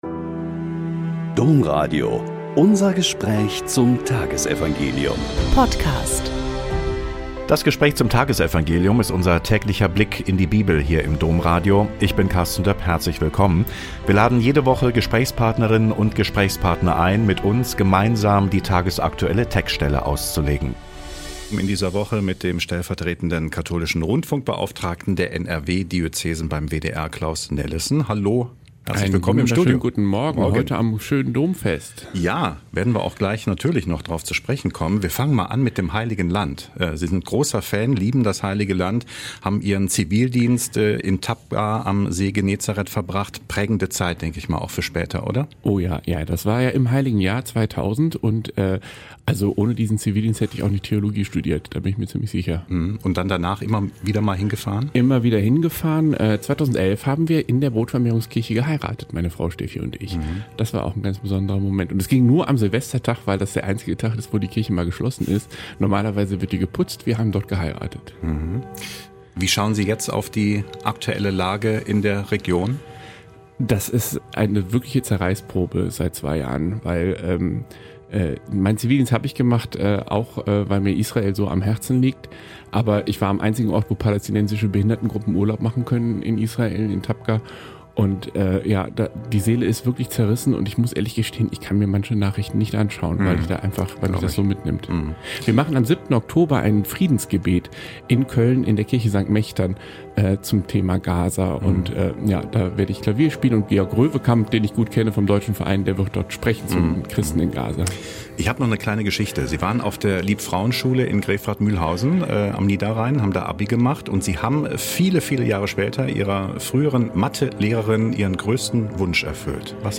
Mt 19,13-15 - Gespräch